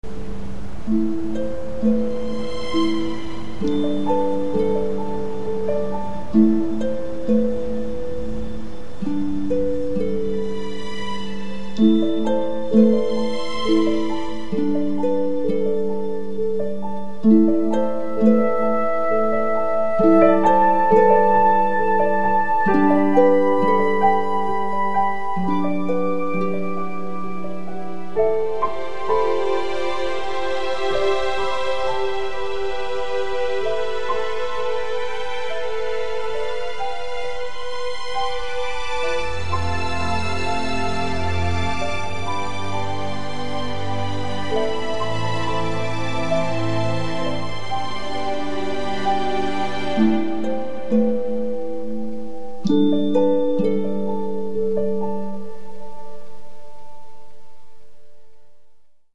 score is very muted